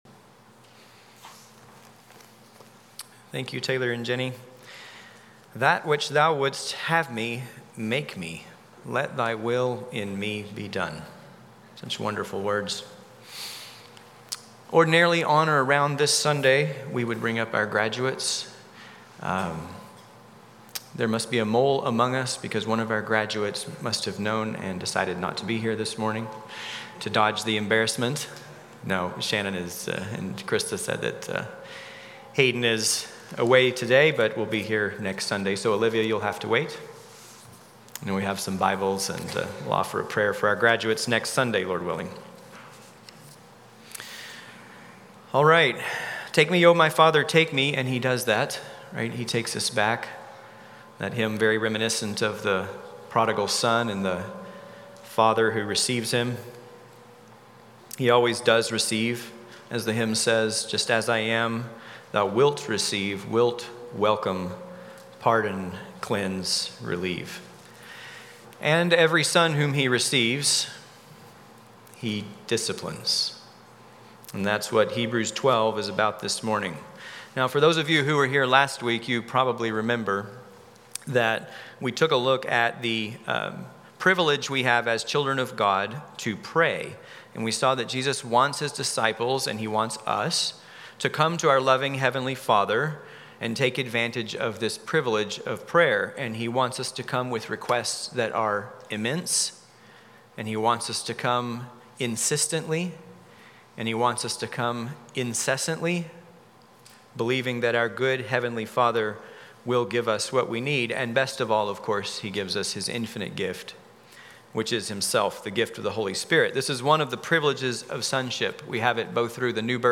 Guest Speakers Passage: Hebrews 12:3-13 Service Type: Morning Worship Service « Lesson 4